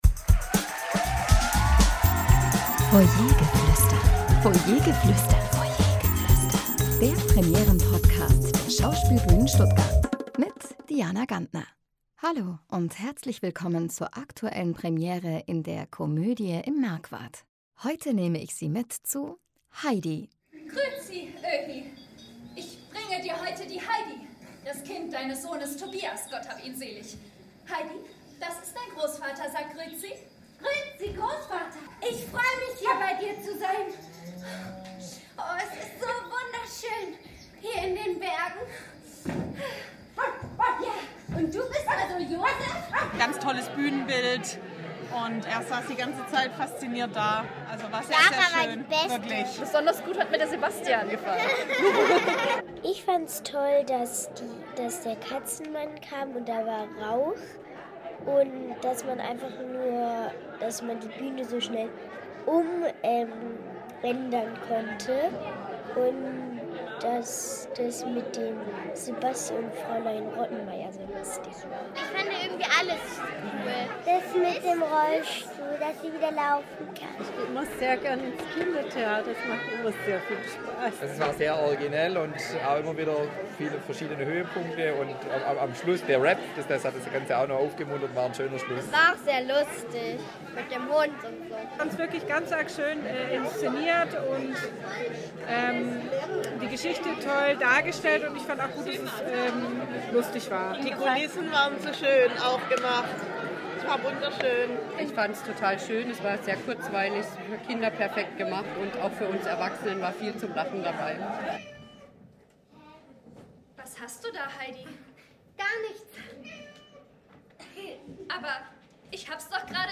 Zuschauerstimmen zur Premiere von “Heidi”